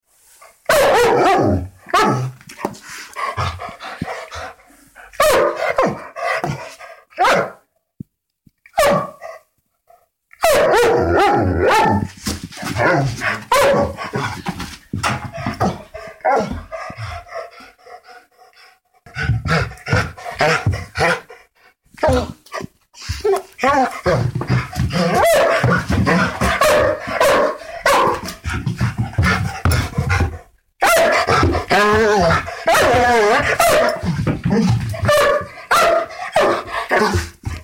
Large Dog Panting And Barking Sound Button - Free Download & Play